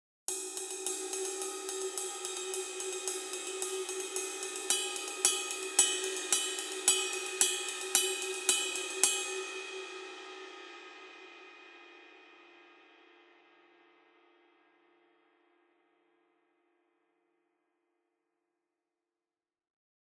Тарелки серии Custom обладают широким частотным диапазоном, теплым плотным звуком и выдающейся музыкальностью.
Masterwork 20 Custom Ride sample
Custom-Ride-20.mp3